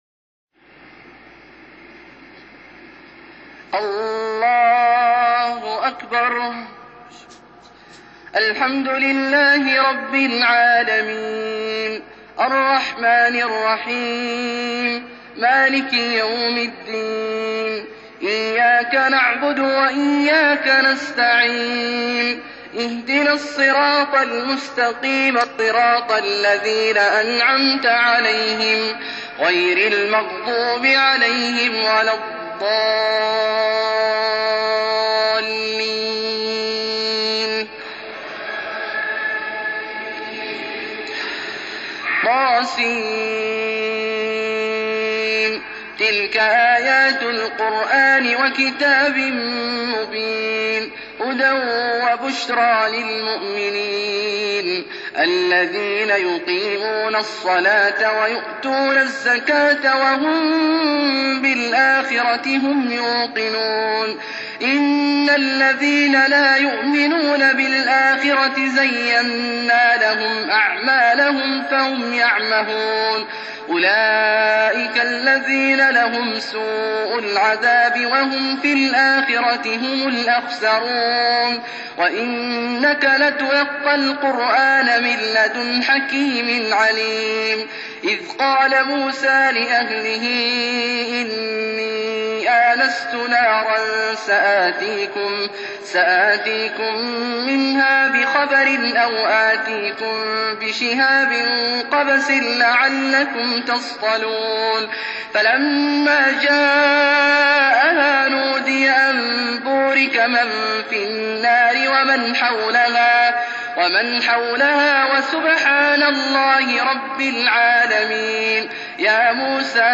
تلاوة من مسجد القبلتين عام 1416 سورة النمل > تلاوات مسجد قباء و القبلتين > المزيد - تلاوات عبدالله الجهني